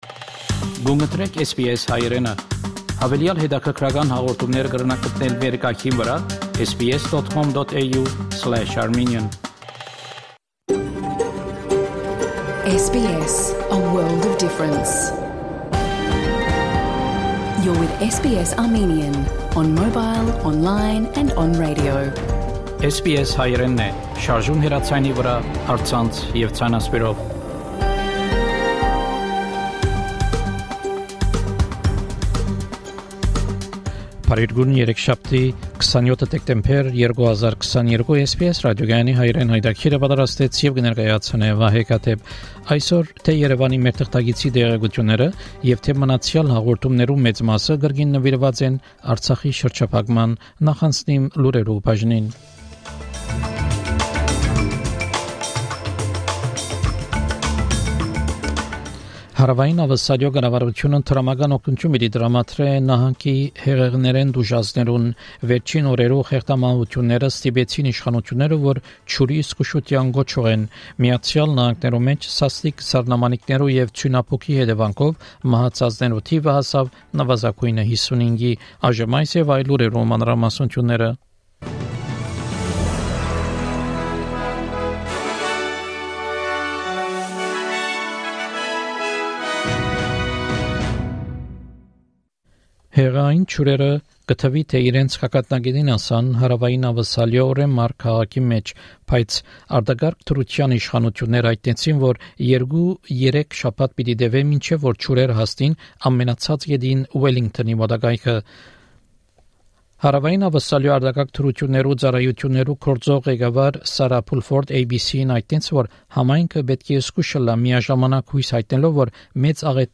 SBS Armenian news bulletin – 27 December 2022